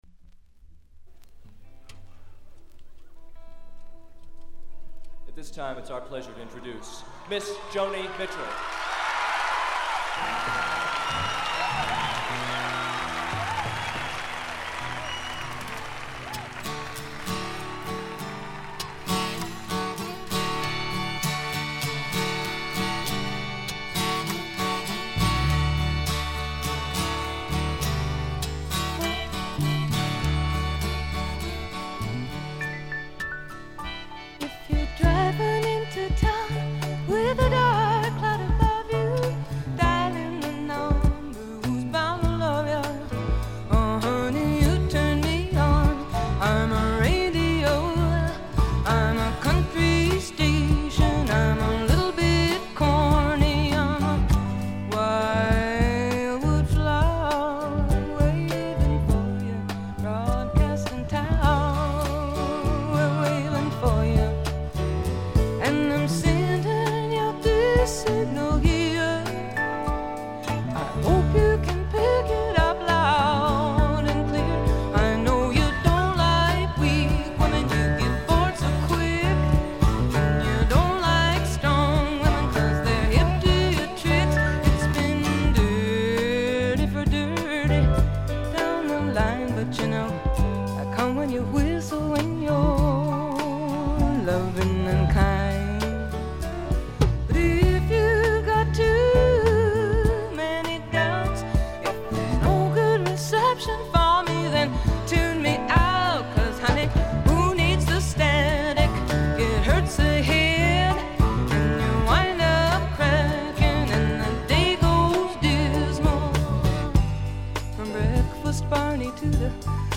ところどころの部分試聴ですが、わずかなチリプチ程度でほとんどノイズ感無し。
試聴曲は現品からの取り込み音源です。